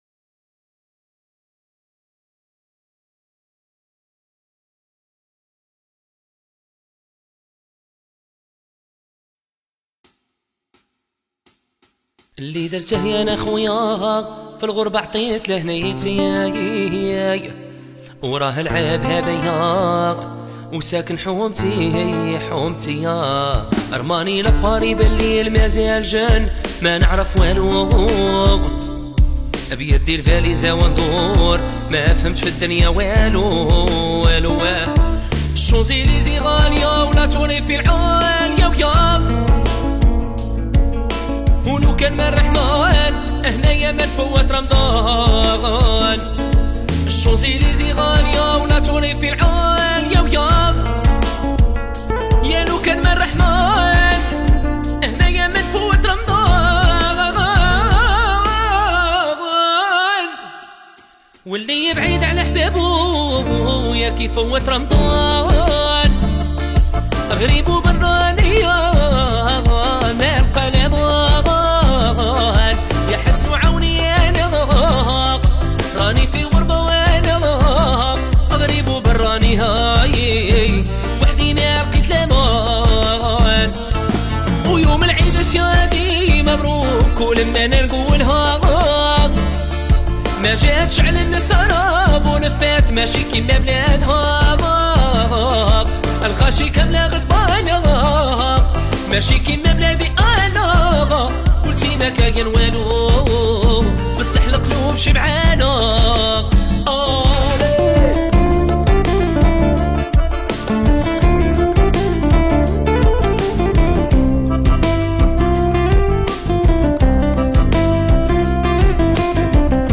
أغاني مغربية